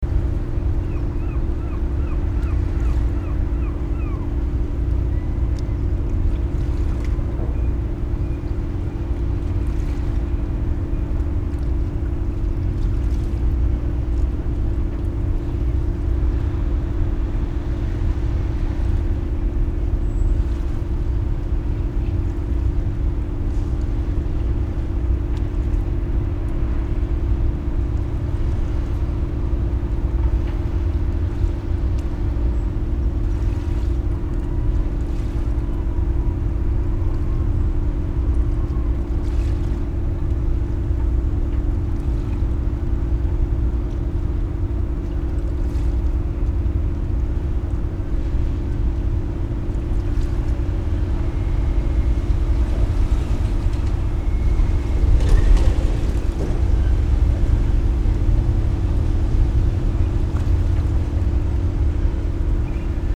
blustery abstraction of crackle, hiss and vibration